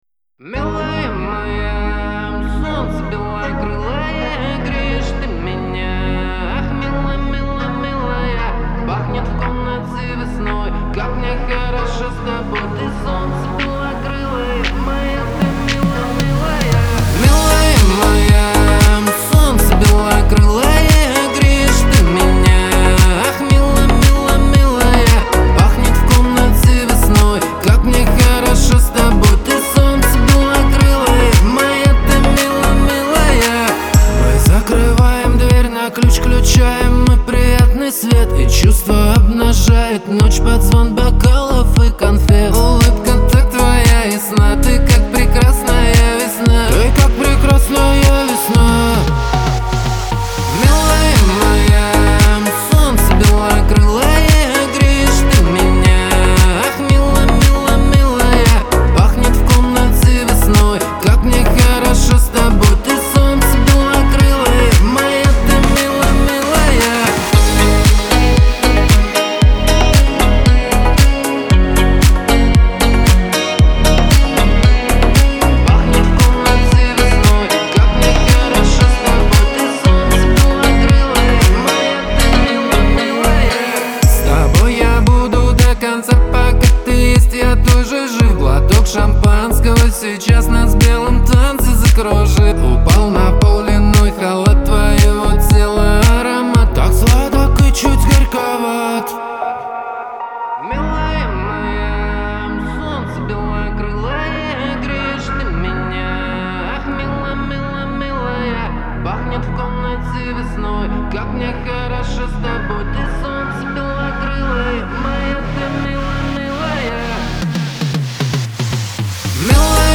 Голос как из подземелья